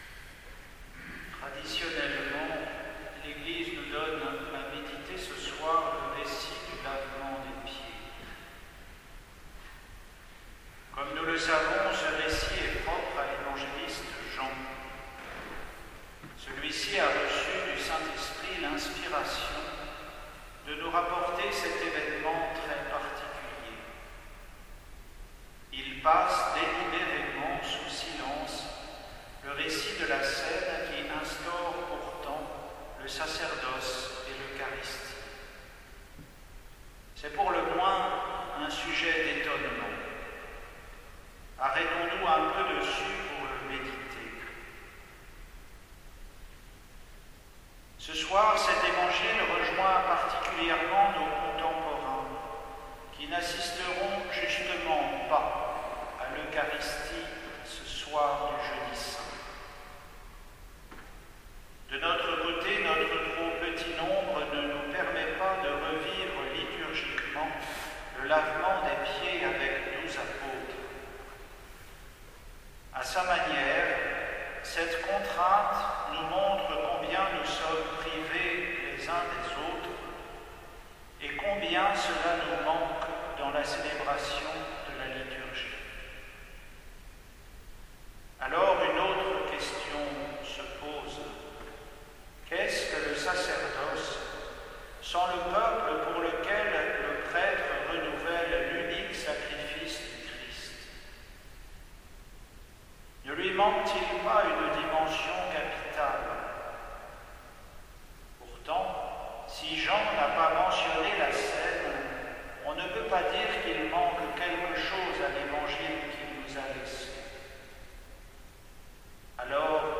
Homélie pour le Jeudi saint in Cæna Domini, 9 avril 2020